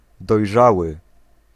Ääntäminen
US : IPA : [raɪp]